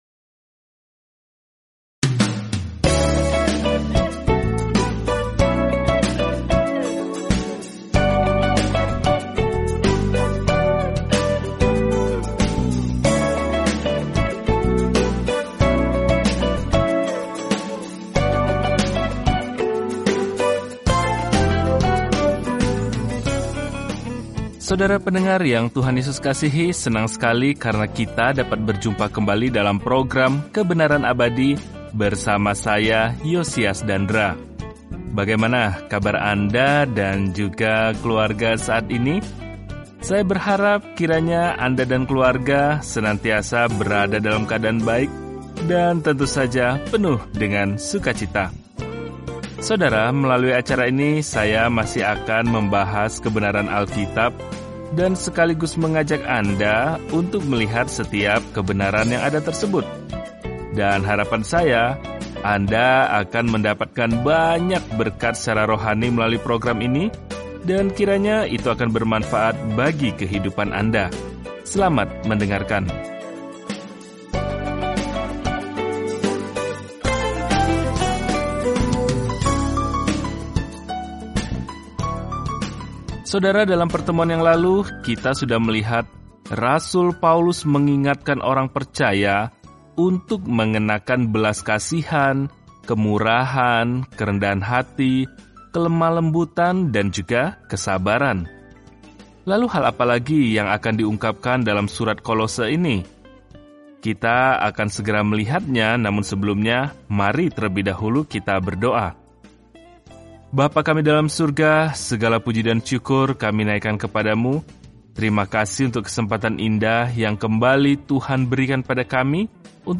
Firman Tuhan, Alkitab Kolose 3:13-25 Hari 9 Mulai Rencana ini Hari 11 Tentang Rencana ini “Utamakanlah Yesus” adalah fokus dari surat kepada jemaat Kolose, yang menawarkan bantuan bagaimana berjalan dalam identitas penuh dengan Kristus. Jelajahi Kolose setiap hari sambil mendengarkan pelajaran audio dan membaca ayat-ayat tertentu dari firman Tuhan.